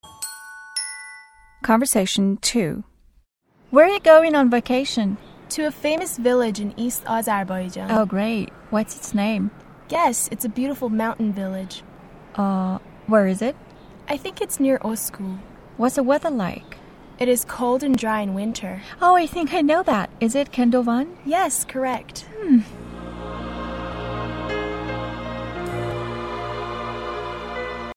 مکالمه ی دوم - متن لیسنینگ listening هشتم